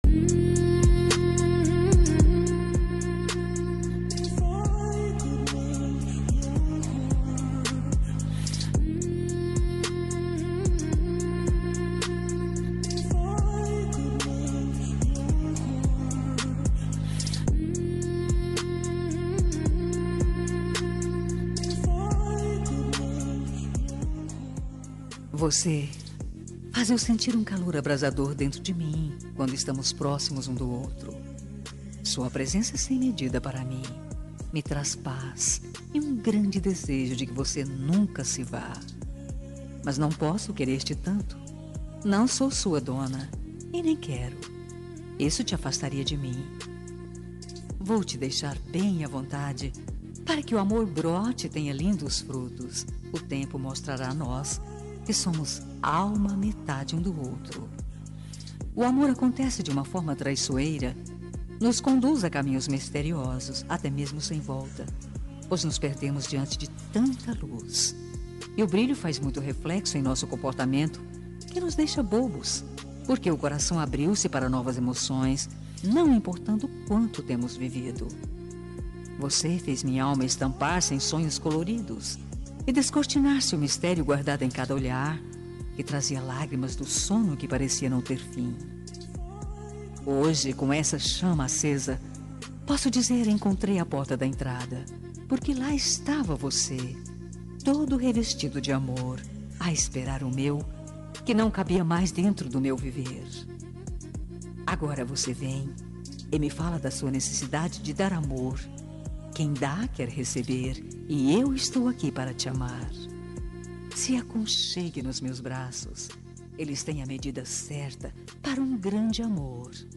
Telemensagem Romântica – Marido – Voz Feminina – Cód: 7862